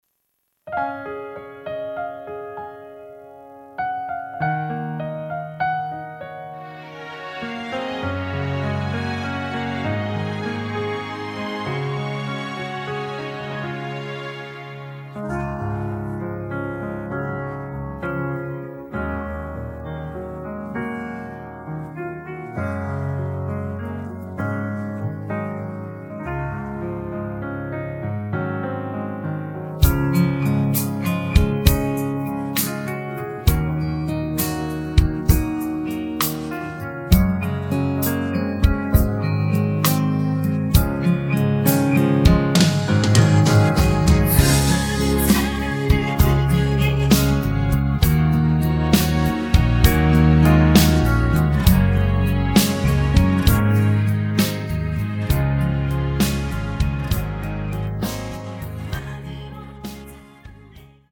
음정 원키 3:44
장르 가요 구분 Voice MR